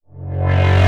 VEC3 Reverse FX
VEC3 FX Reverse 20.wav